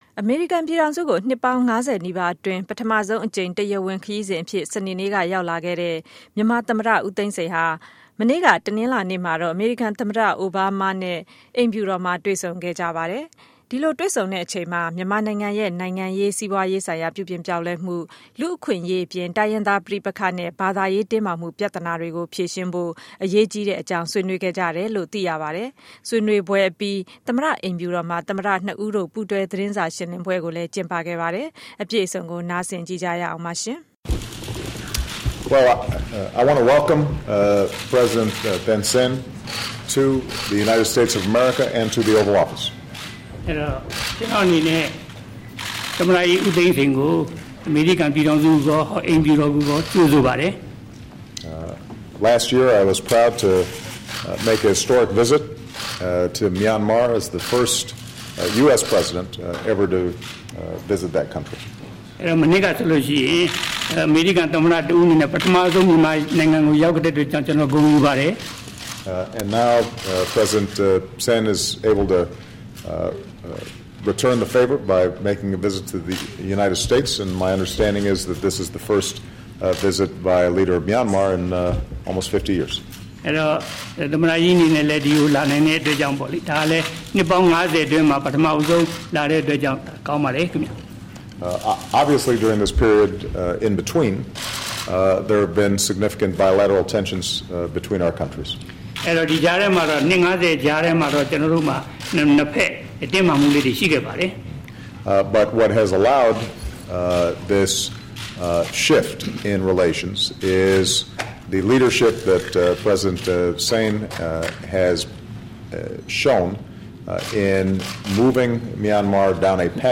သိန်းစိန် အိုဘားမား သတင်းစာရှင်းပွဲ